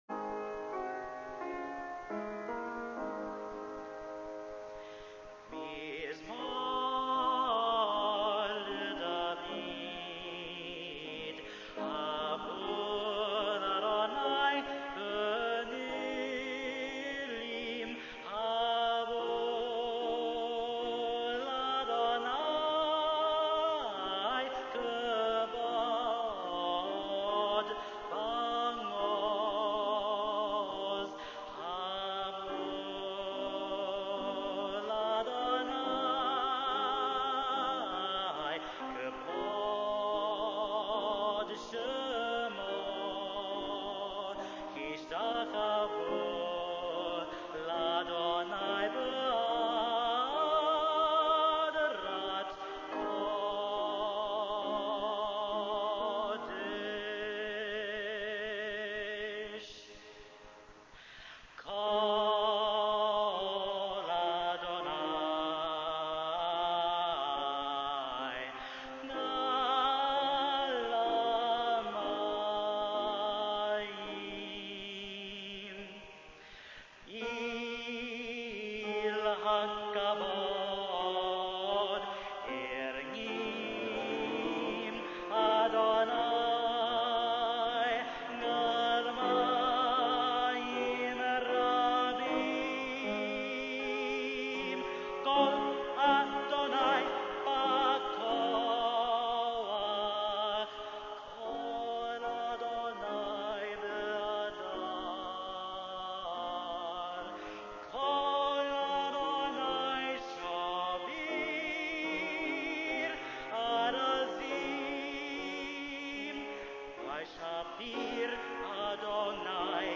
zang